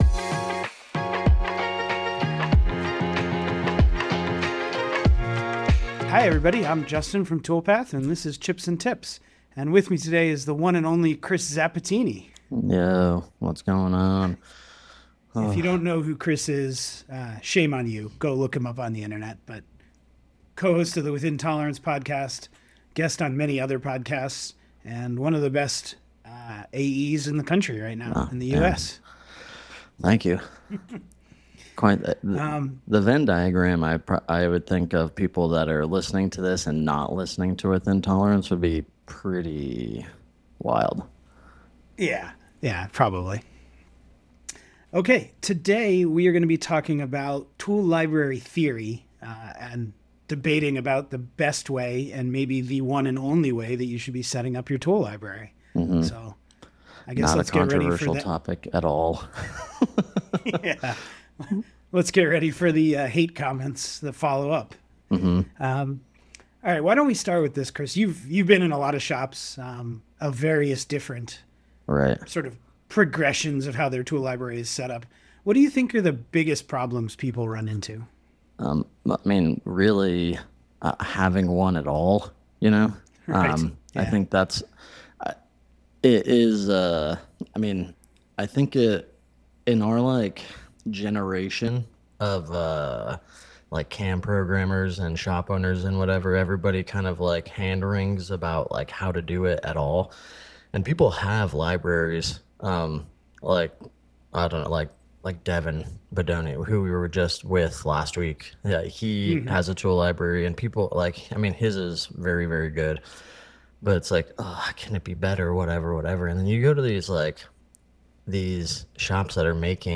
This is Part 1 of our engaging debate—tune in to elevate your understanding of tool management!